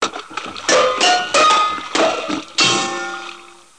00099_Sound_building.mp3